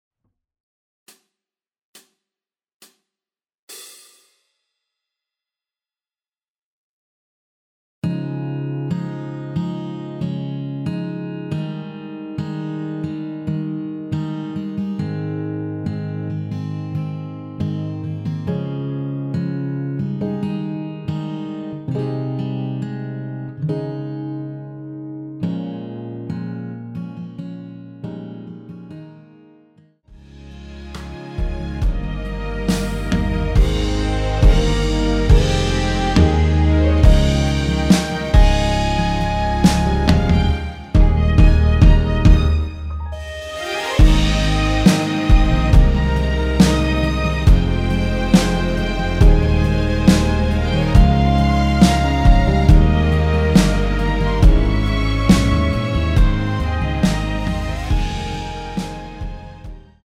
전주 없이 시작하는 곡이라서 시작 카운트 만들어놓았습니다.(미리듣기 확인)
Ab
앞부분30초, 뒷부분30초씩 편집해서 올려 드리고 있습니다.